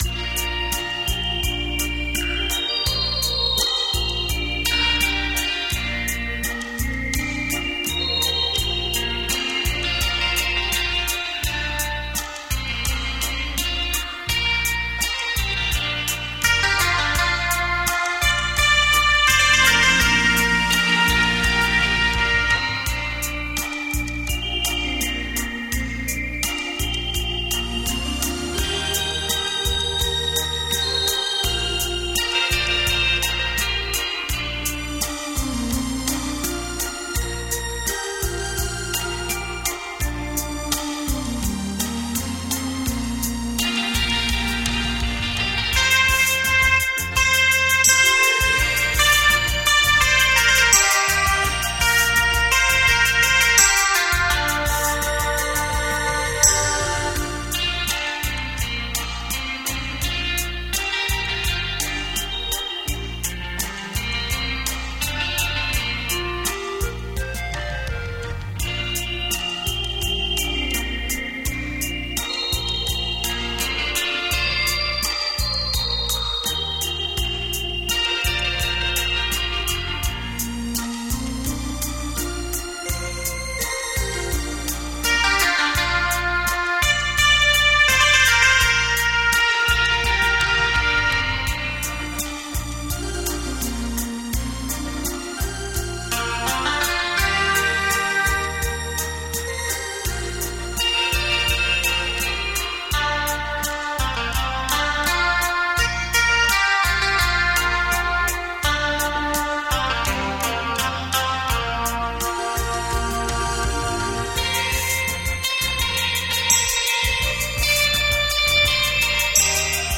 优美的旋律 清脆的音感